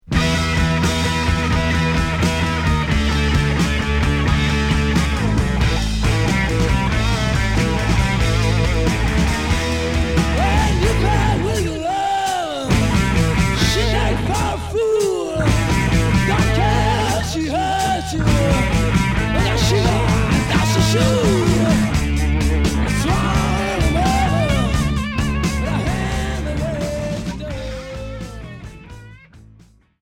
Heavy rock bluesy